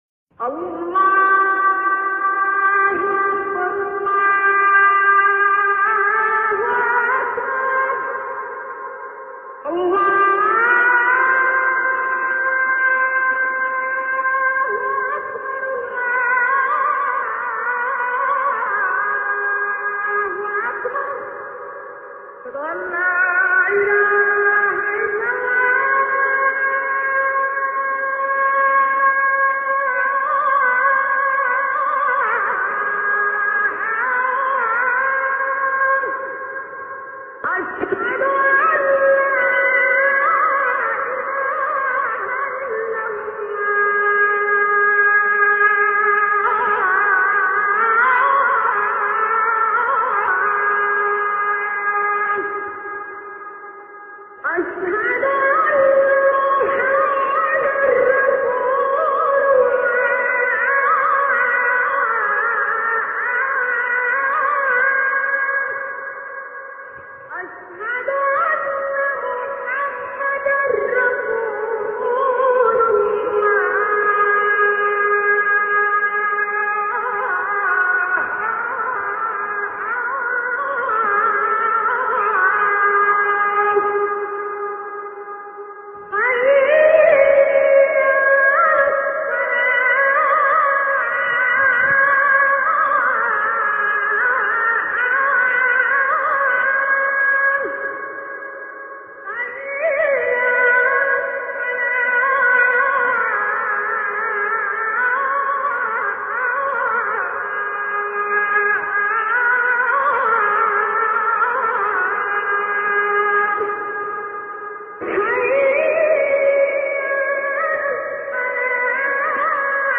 کلمات دلیلیة: المسجد النبوي الشريف ، رفع الأذان ، المقرئ الراحل ، المؤذن